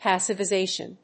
音節pas･siv･i･za･tion発音記号・読み方pæ̀sɪvəzéɪʃ(ə)n|-vaɪ-